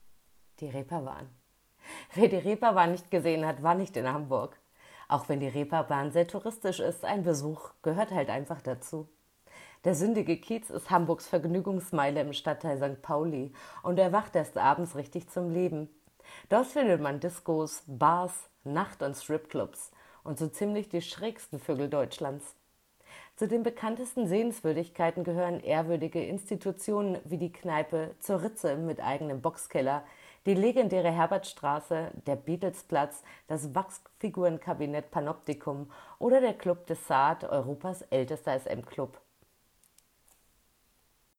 • Sprachbeispiele zu Sehenswürdigkeiten in Hamburg